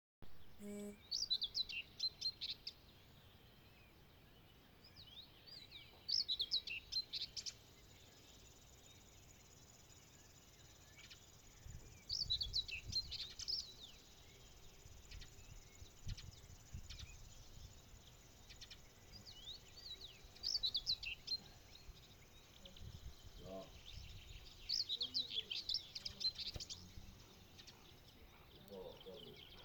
Putni -> Ķauķi ->
Kārklu ķauķis, Locustella naevia
StatussDzied ligzdošanai piemērotā biotopā (D)